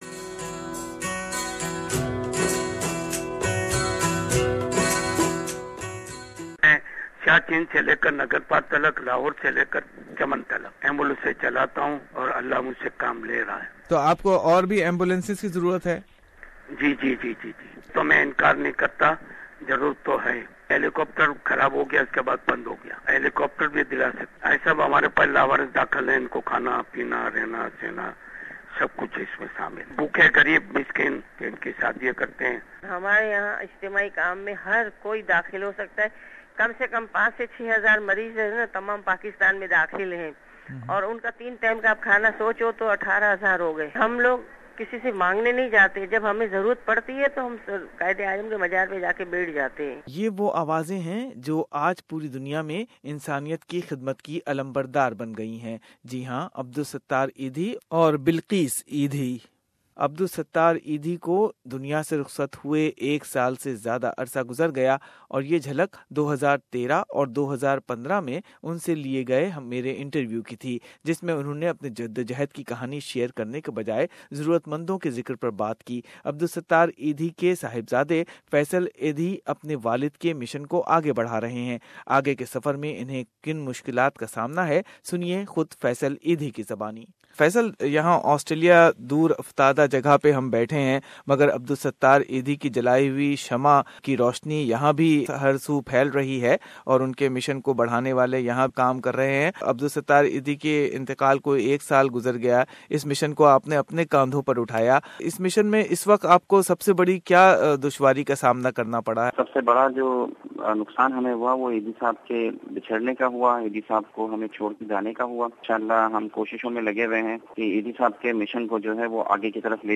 Philanthropist Abdul Sattar Edhi is no more in the world but his vision is alive and spreading even here in Australia. Edhi International Foundation Australia is increasing its efforts since its establishment to support Edhi foundation Pakistan charity work and this year is no more different. Feature includes voices of Adul sattar Edhi (Late) Bilqis Edhi and message of Edhi's son Faisal Edhi who is taking his fathers mission ahead.